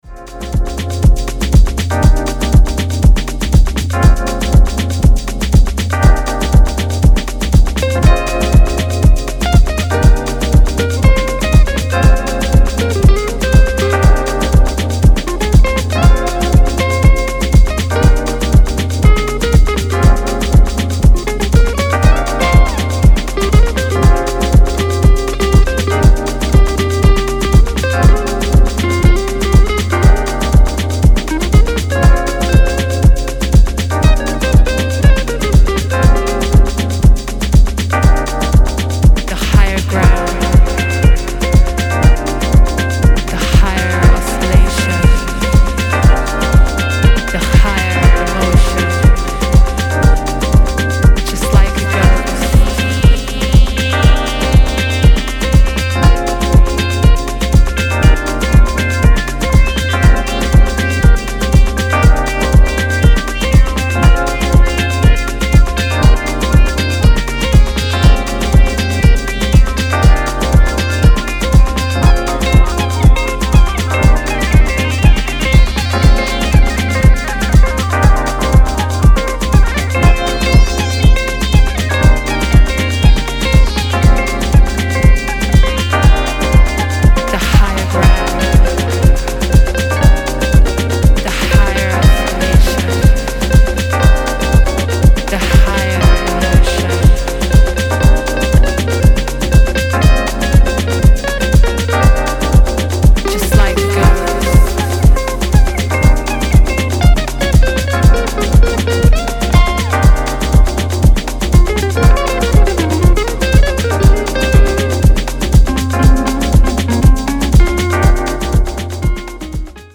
which is an uplifting deep winner with a Mediterranean feel.